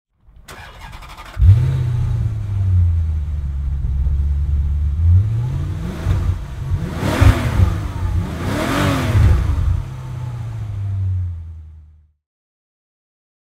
Maserati Spyder Cambiocorsa (2003) - Starten und Leerlauf
Maserati_Spyder_2003.mp3